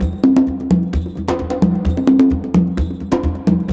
CONGABEAT4-L.wav